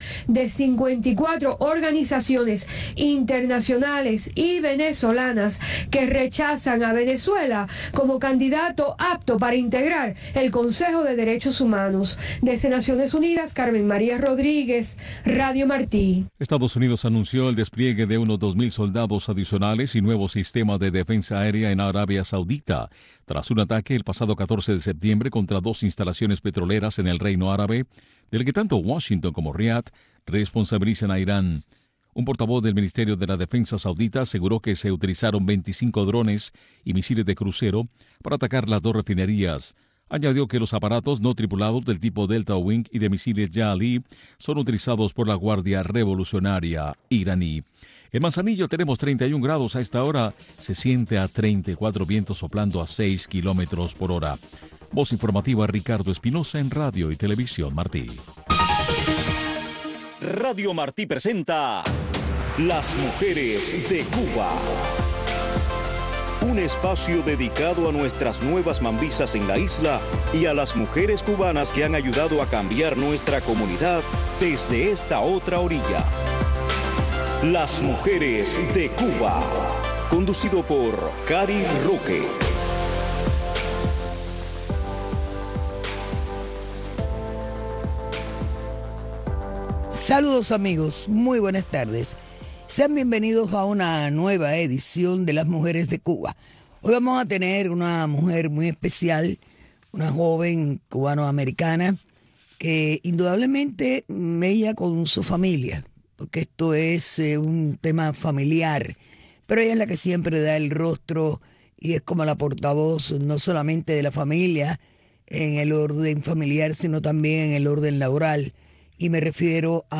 Un programa que busca resaltar a las mujeres cubanas que marcan pauta en nuestra comunidad y en la isla. Y es un acercamiento a sus raíces, sus historias de éxitos y sus comienzos en la lucha contra la dictadura. Un programa narrado en primera persona por las protagonistas de nuestra historia.